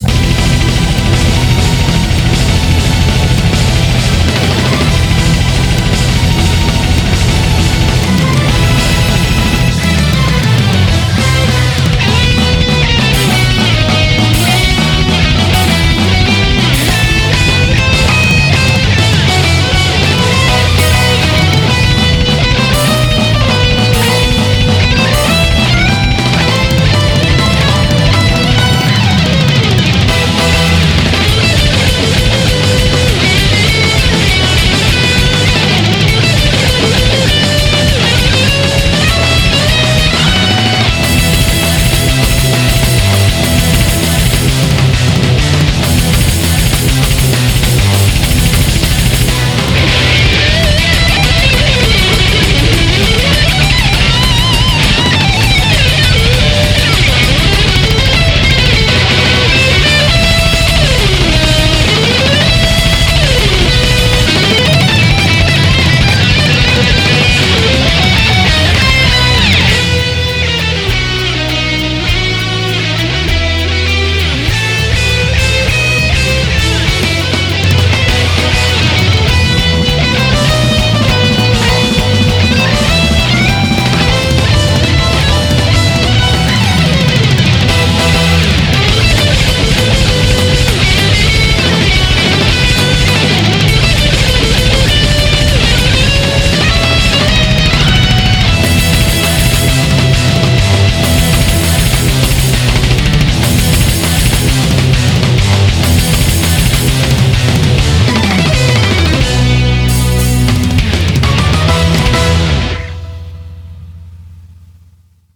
BPM200
Audio QualityLine Out